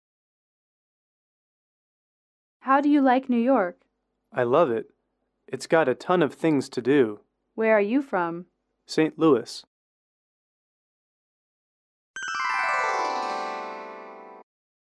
英语主题情景短对话55-1：纽约的感受(MP3)